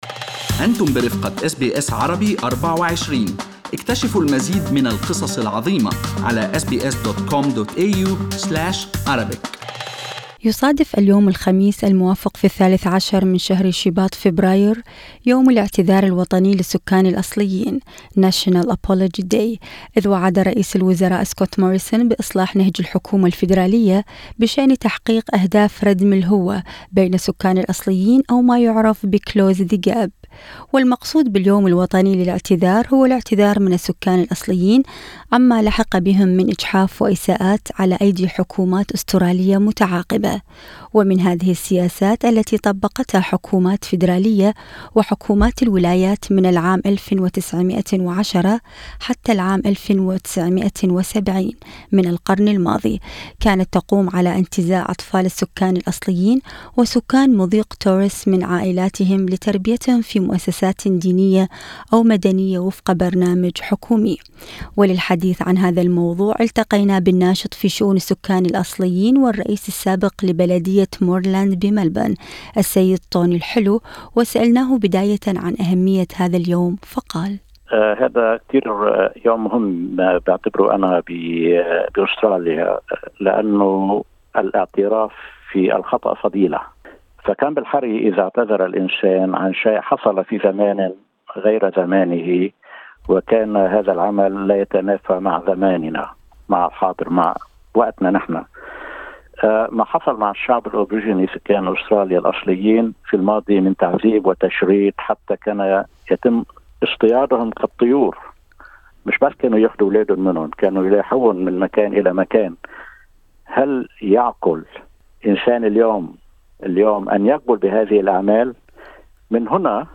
Minister for Indigenous Australians Ken Wyatt Source: AAP وللحديث عن هذا الموضوع، التقينا بالناشط في شؤون السكان الأصليين والرئيس السابق لبلدية Moreland بملبورن السيد طوني الحلو الذي قال ان هذا اليوم مهم جداً، وعلى أستراليا ان تقدم المزيد للسكان الأصليين.